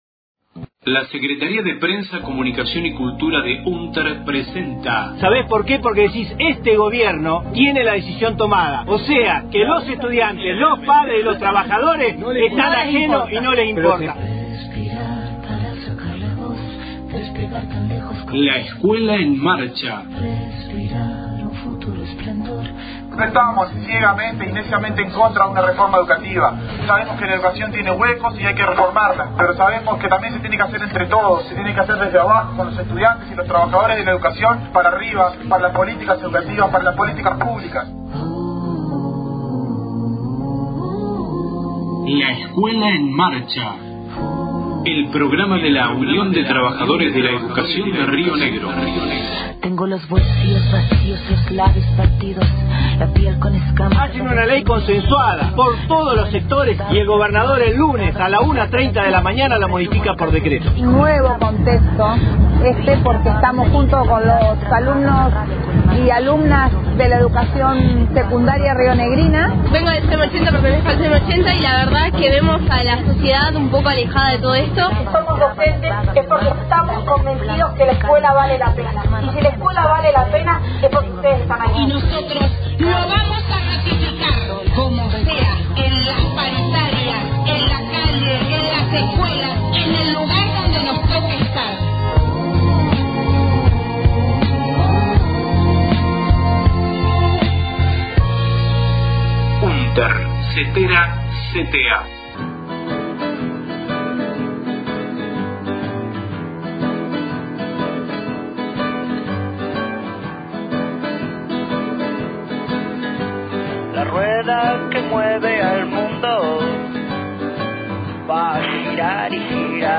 LEEM 03/12/18 Audio acto Frente Sindical y Social luego de la marcha en repudio al G20, realizada en Roca – Fiske Menuco, el 30/11/18.